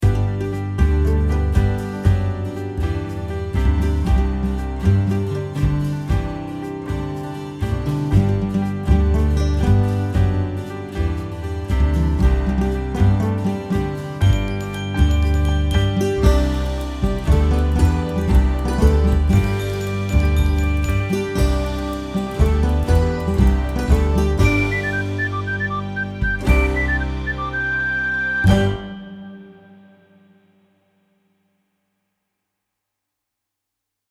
(Here’s the music bed for the ad. I don’t yet have permission to post the whole thing, but the music is mine.)